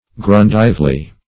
Search Result for " gerundively" : The Collaborative International Dictionary of English v.0.48: Gerundively \Ge*run"dive*ly\, adv. In the manner of a gerund; as, or in place of, a gerund.